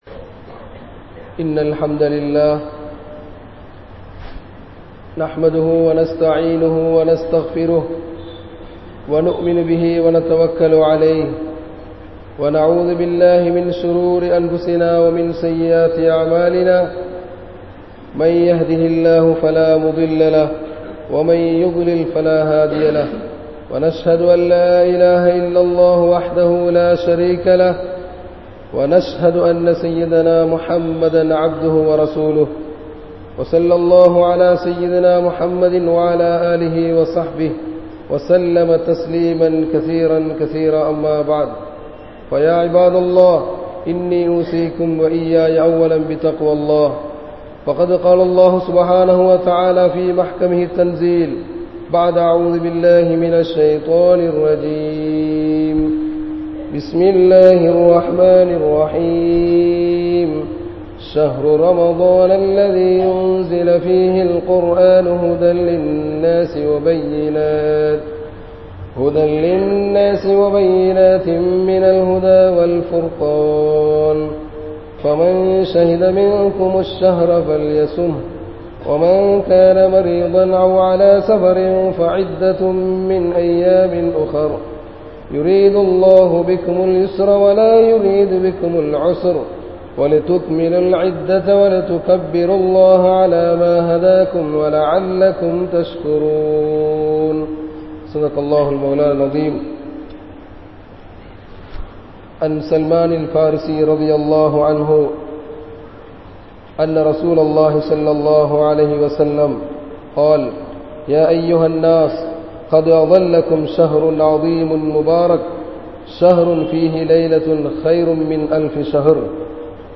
Ramalaanin Sirappuhal (ரமழானின் சிறப்புகள்) | Audio Bayans | All Ceylon Muslim Youth Community | Addalaichenai
Grand Jumua Masjith